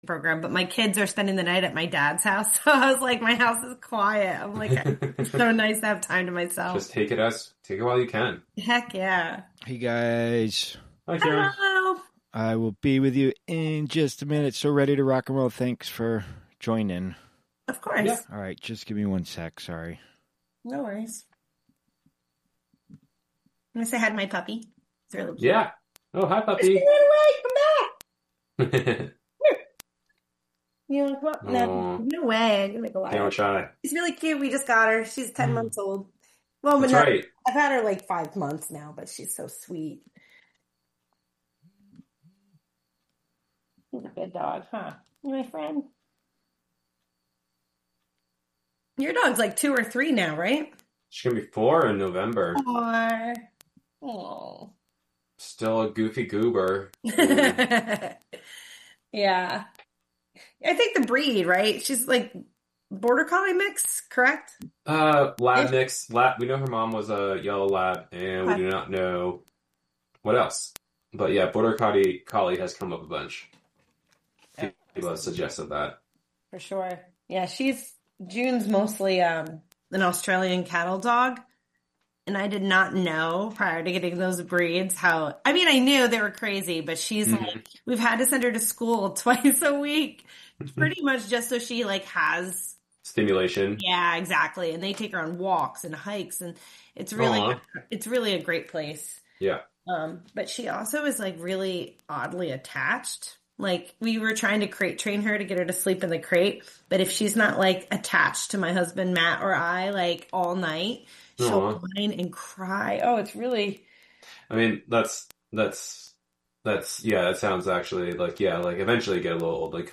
CCSM was gifted a $250K donation from an anonymous donor that wishes us to match the donation in order for us to unlock the full amount. Tune in for a mix of music themed on current events, interviews with artists, musicians, community members, and more live from Housatonic, Mass.